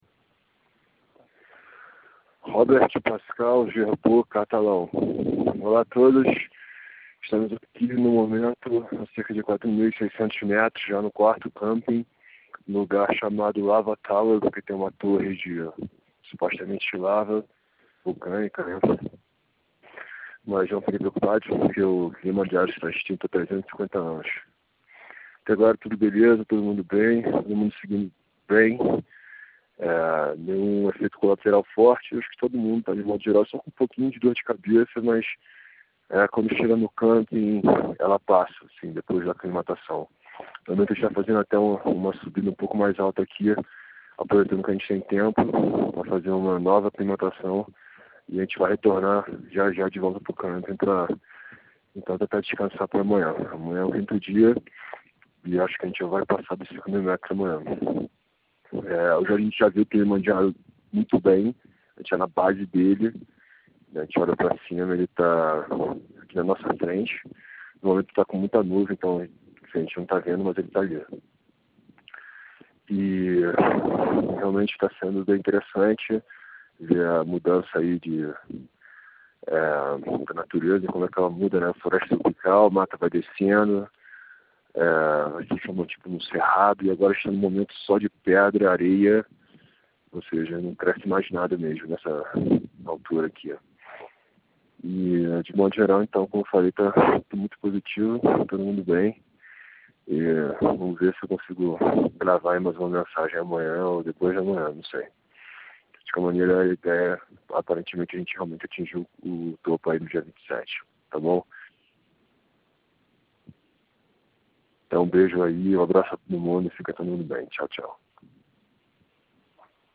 We are here at the moment at about 4,600m, in the fourth camp at a place called "Lava Tower" because it supposedly has a tower made of volcanic lava.